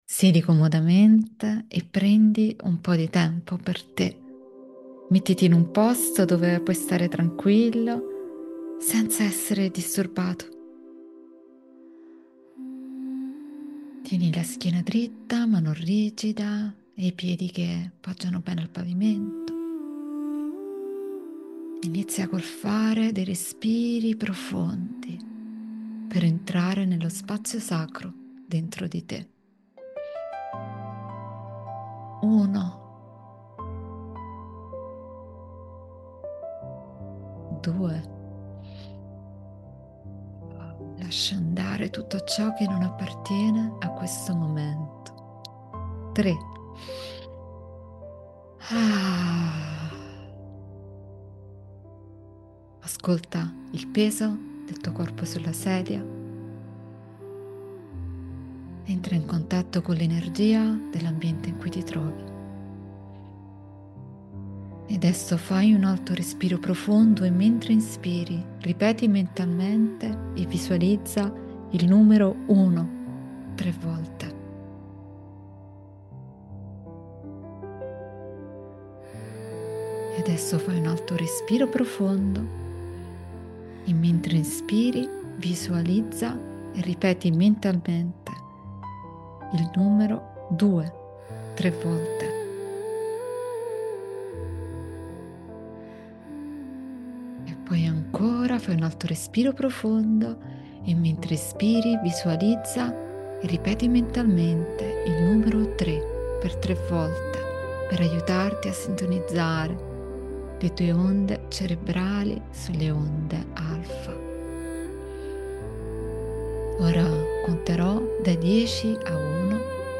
Meditazione-quantica.mp3